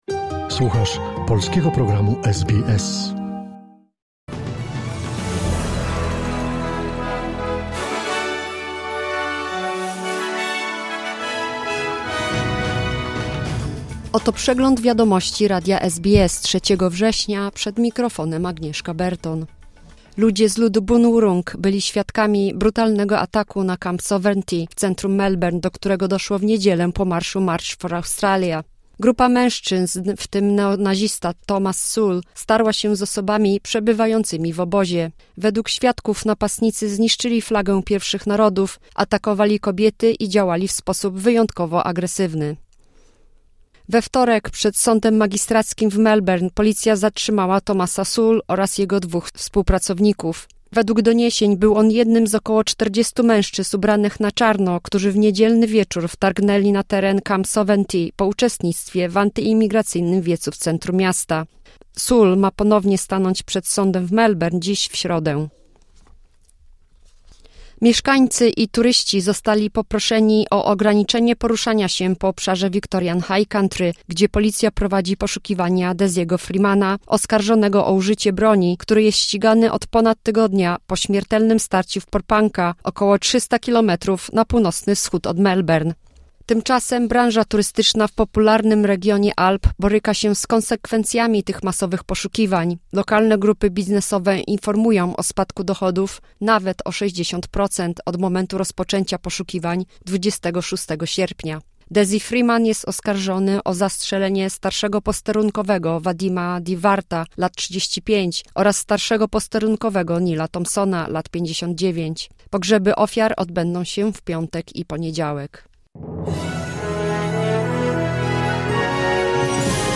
Wiadomości 3 września SBS News Flash